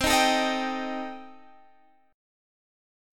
Cm6 Chord
Listen to Cm6 strummed